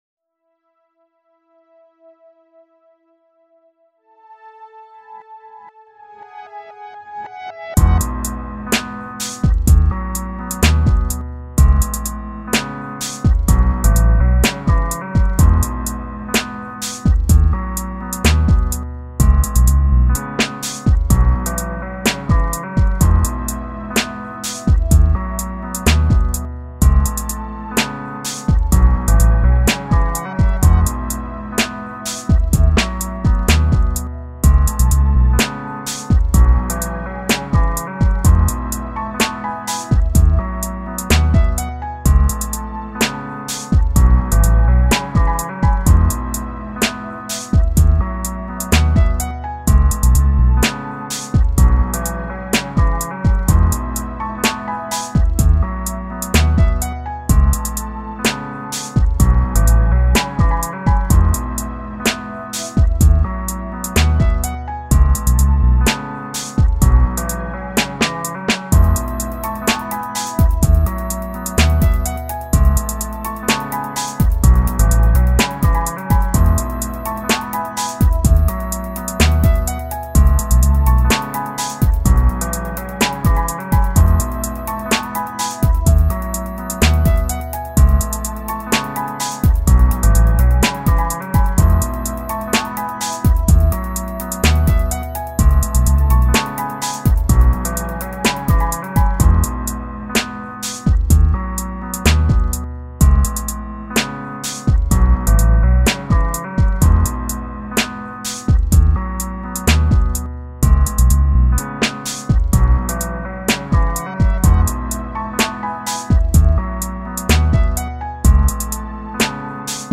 Here is a soft rap beat for Valentine's Day.Â  There is a bass, sampled guitar (pitch bent Modest Mouse guitar), synths, drums -- two high hats, three bass drums, and like four or five snares layered on top of one another.Â  With all the layering, I was hoping this beat would sound beefier, but it still sounds a little weak to me.
Filed under: Instrumental | Comments (1)